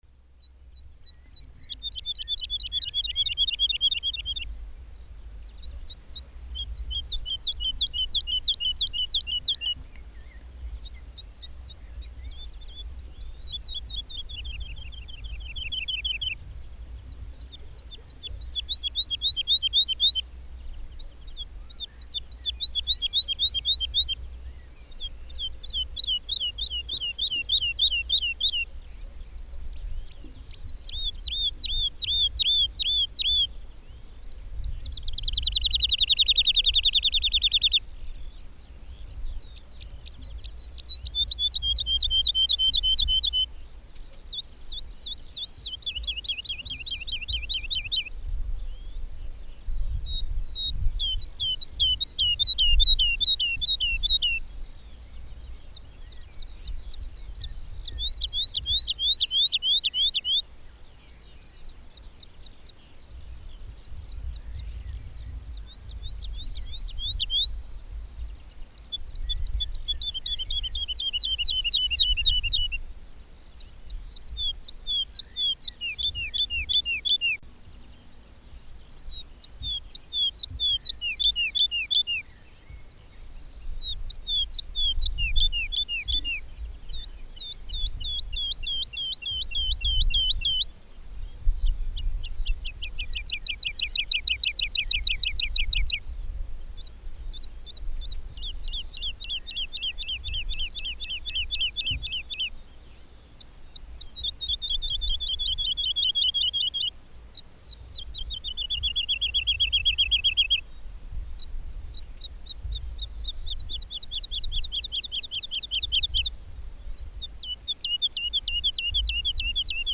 Ciocarlia-4-de-padure-Lullula-arborea.mp3